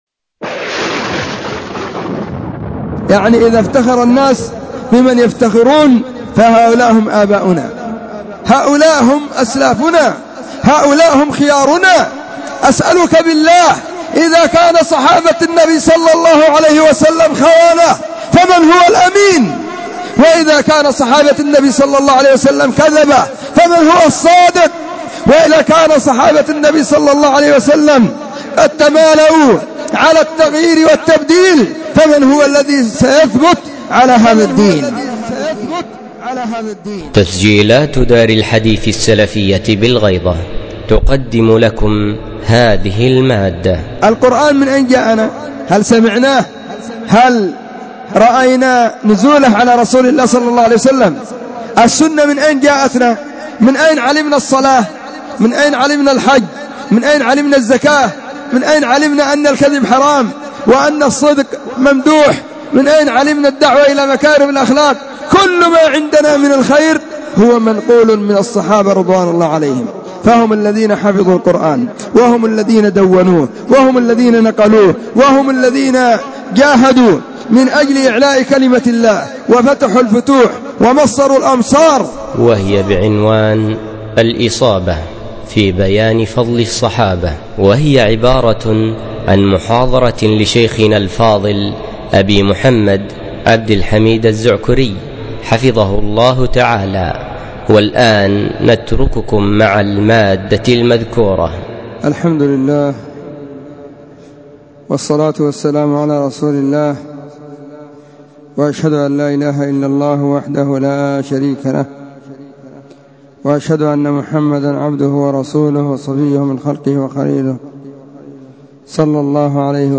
محاضرة بعنوان (( الإصابة في بيان فضل الصحابة ))
📢 مسجد الصحابة – بالغيضة – المهرة، اليمن حرسها الله،